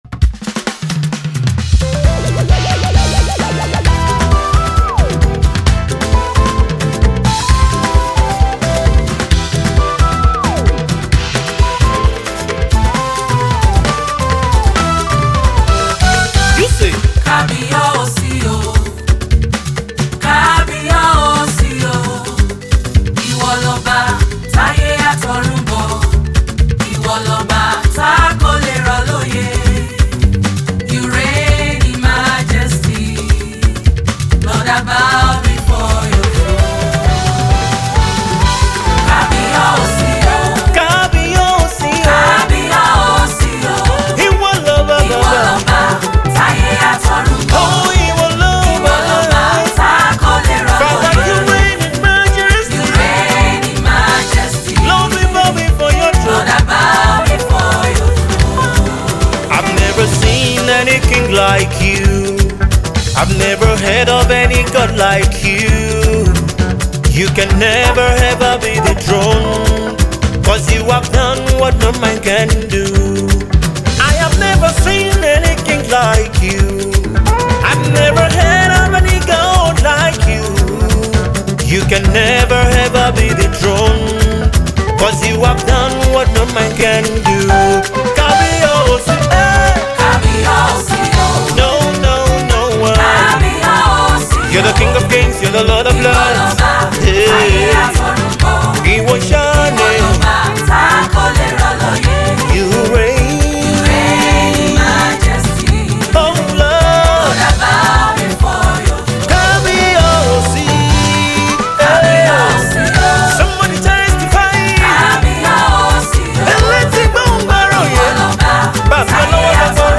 Be blessed as you listen and dance along!